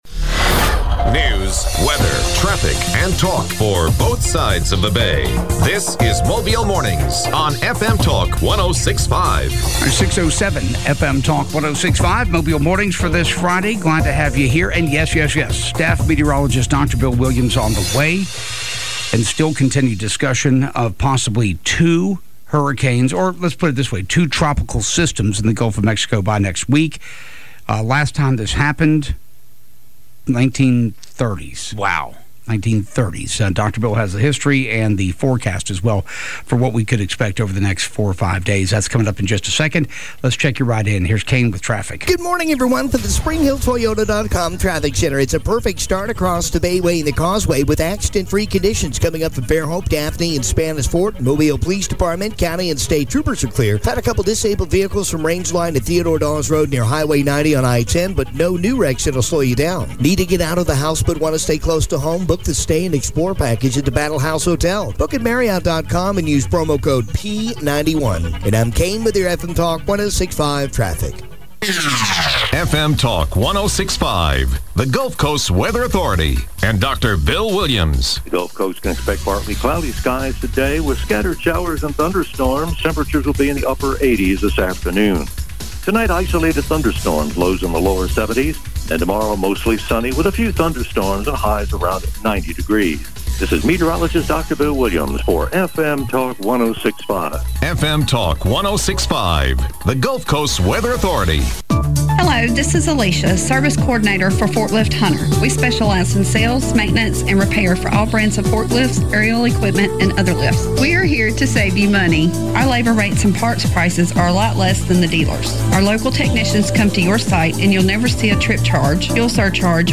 report on local news and sports
reports on traffic conditions